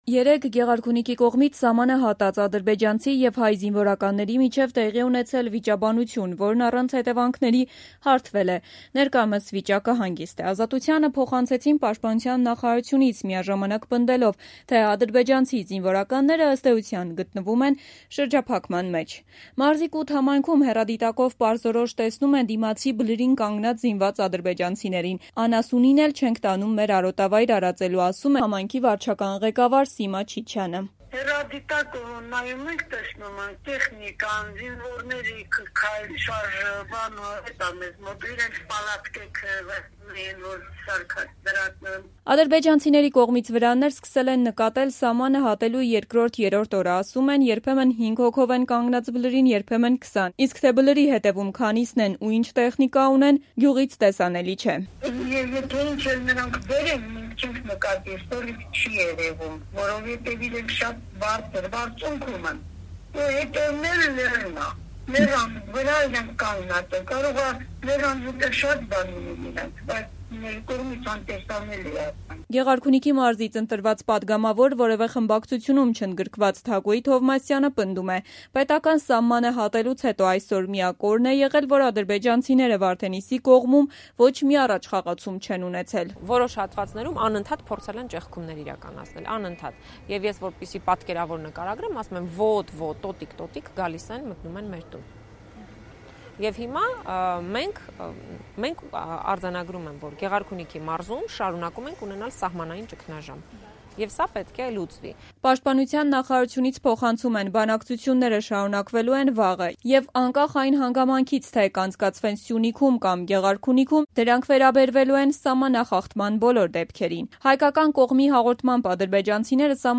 Նախկին արոտավայրերում անասունին էլ չենք տանում արածելու․ Կութ համայնքի վարչական ղեկավար